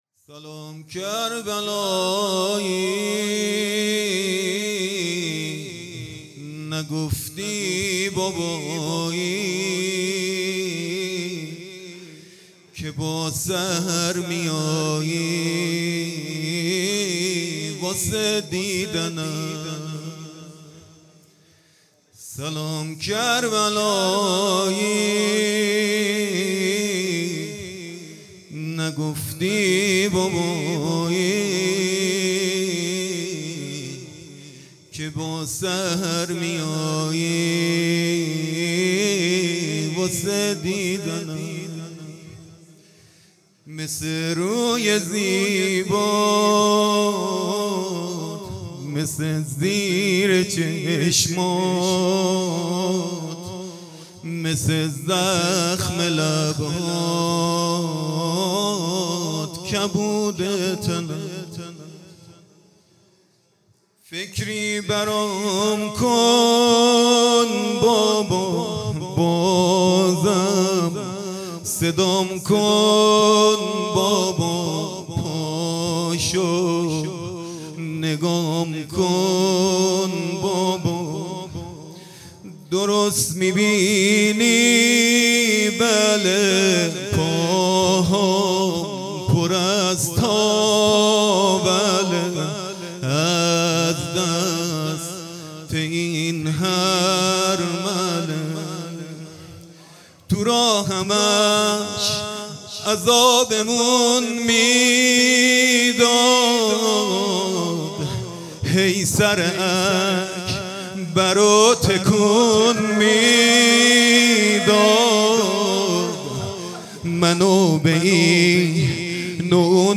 شب سوم محرم الحرام 1446